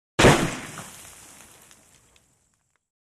Ax hit the trash | Sound effect .mp3 | Download free.
Ax hit the trash: